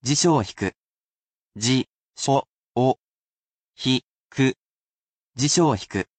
He’ll be here to help sound out these vocabulary words for you.
He’s lovely with tones, as well, and he will read each mora so you can spell it properly in kana.